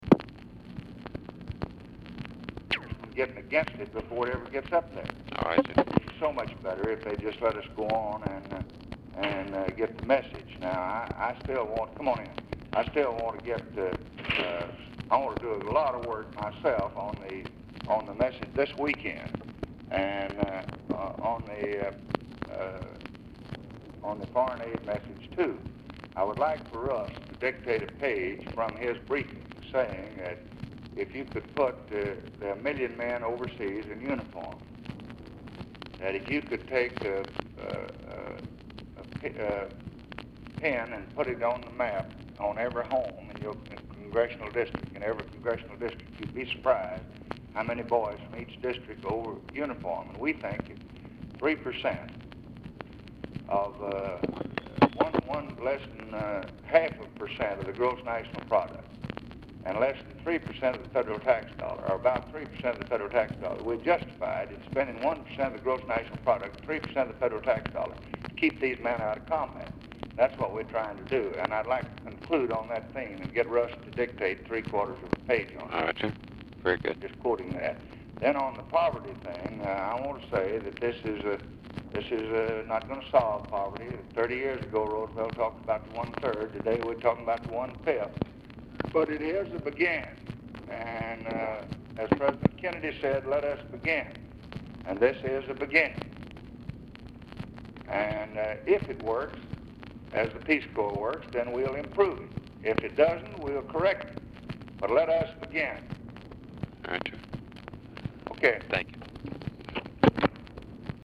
RECORDING STARTS AFTER CALL HAS BEGUN
Format Dictation belt
Location Of Speaker 1 Oval Office or unknown location
Specific Item Type Telephone conversation Subject Defense Federal Budget Foreign Aid Press Relations Lbj Speeches And Statements Welfare And War On Poverty